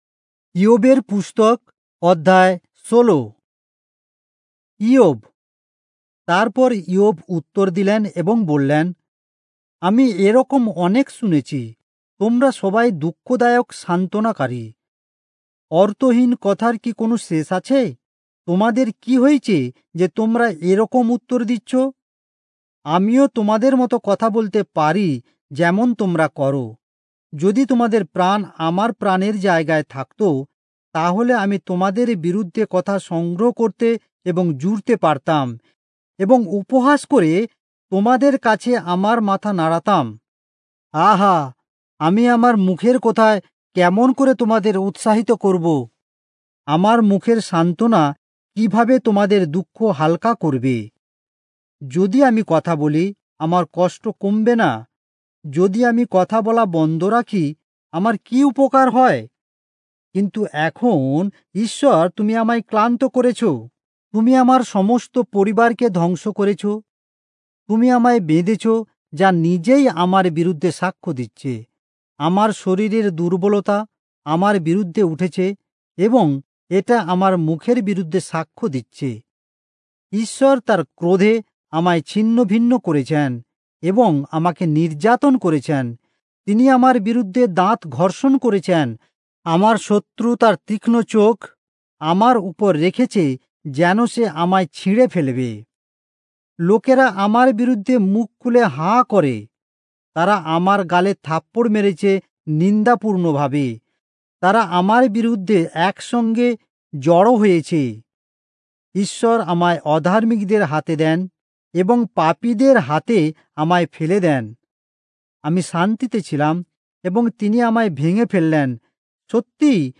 Bengali Audio Bible - Job 9 in Irvbn bible version